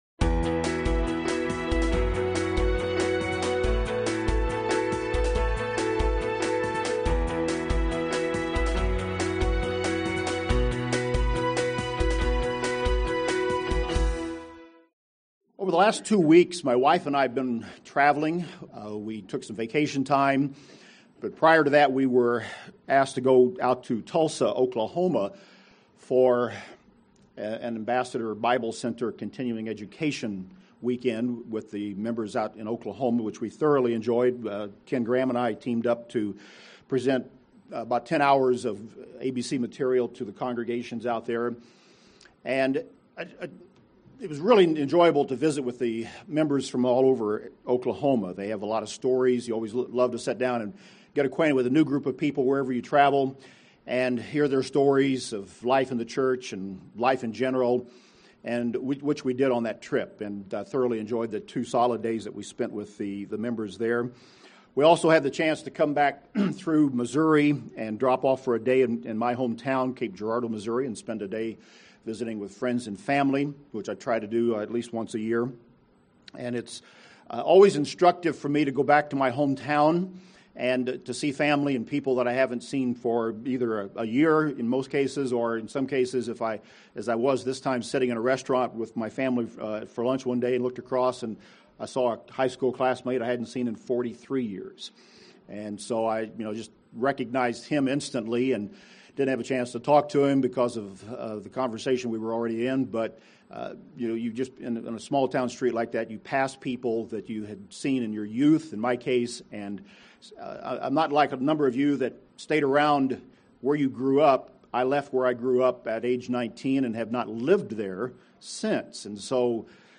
This sermon was given at the Jamaica 2013 Feast site.